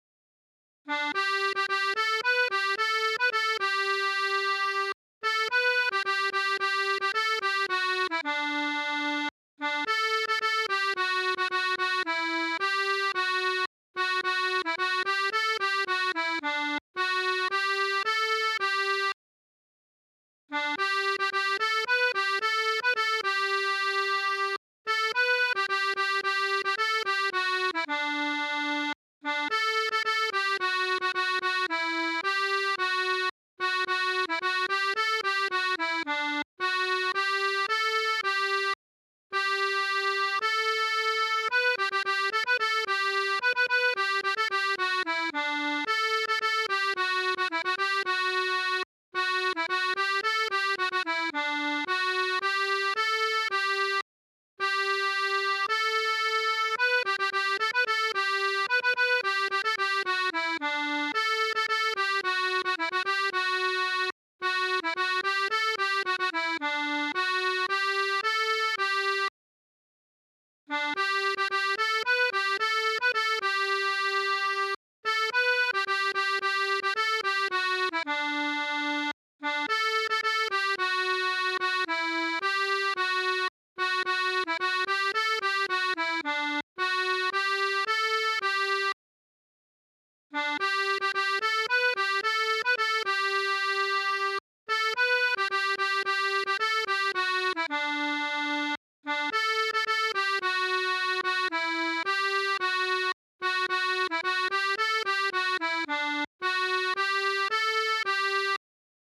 Chants de marins